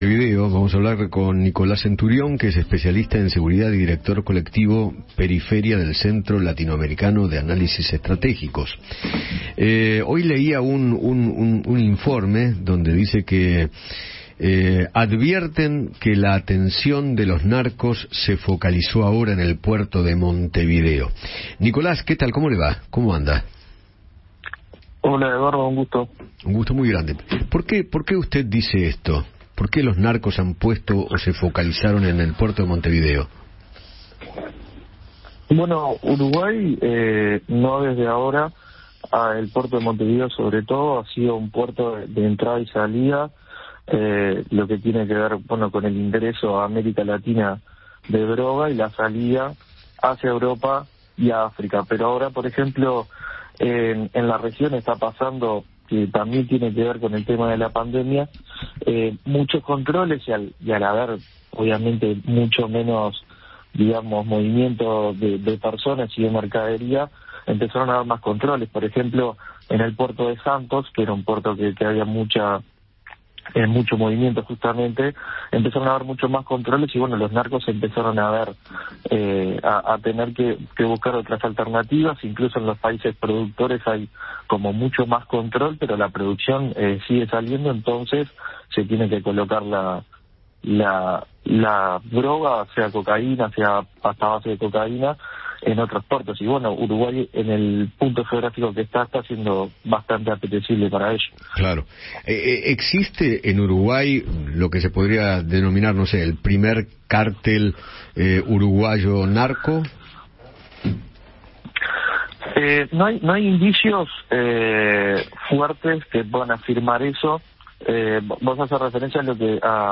conversó con Eduardo Feinmann sobre la situación en Uruguay con respecto al tráfico de drogas y el crimen organizado.